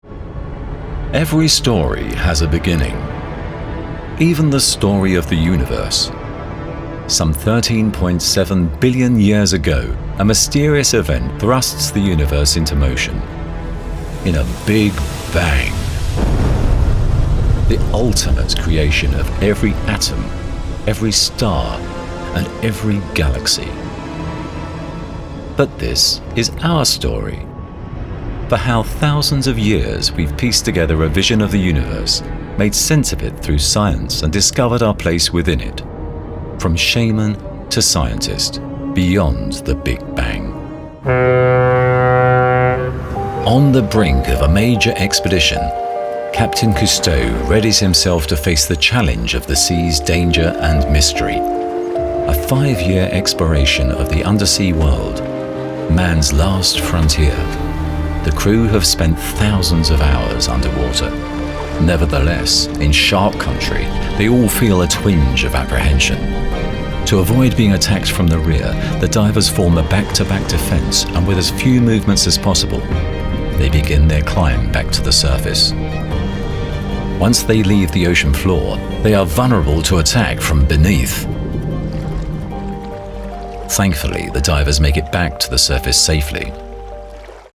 Documentary Reel Sainsburys Buy 6 Radio Advert Sainsburys